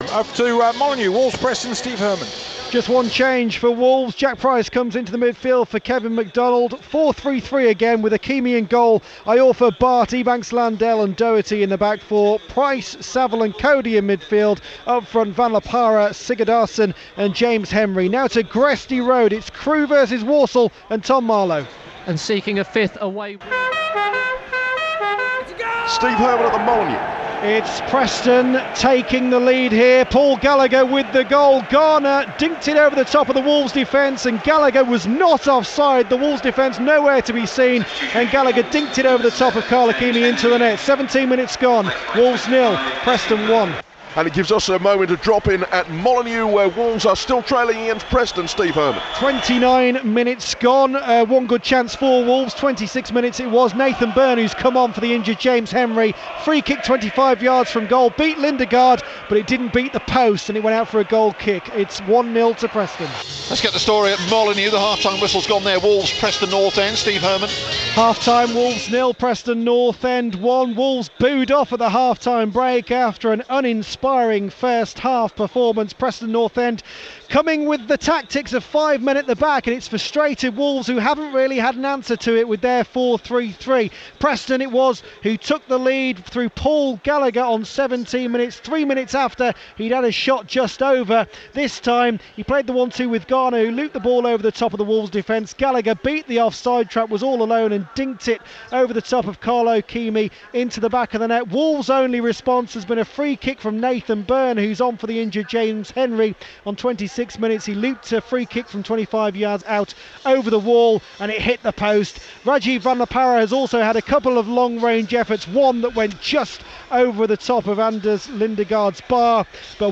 This is from my reports and commentary on BBC WM of Wolves v Preston in the Championship on 13th February 2016.